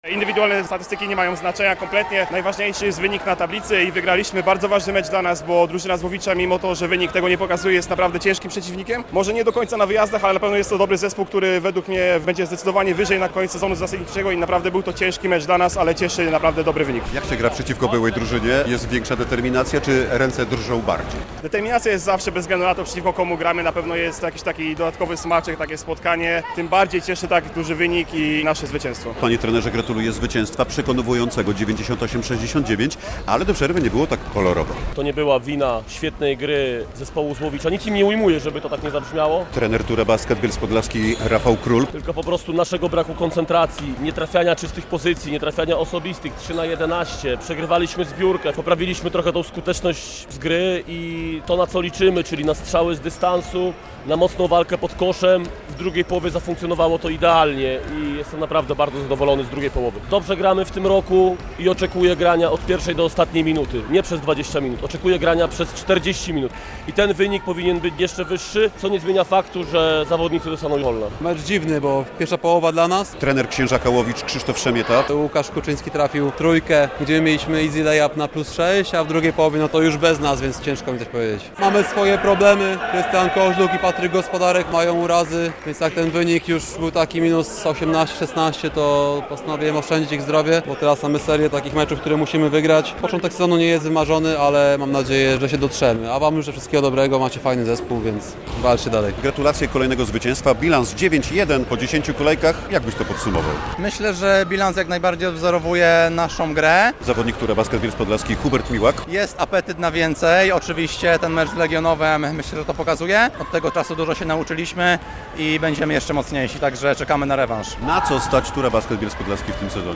Tur Bielsk Podlaski - Księżak Łowicz 98:69 - relacja